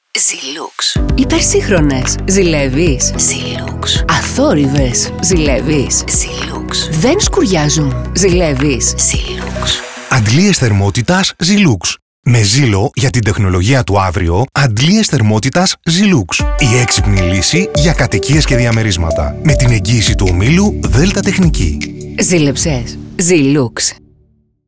Radio Spot: Ο Ζήλος στην τεχνολογία έρχεται με υπογραφή Δέλτα Τεχνική
Η νέα ραδιοφωνική καμπάνια με το χαρακτηριστικό υπαινικτικό ύφος μάς ρωτά ξανά και ξανά: «ΖΗΛΕΥΕΙΣ;» Και δικαίως.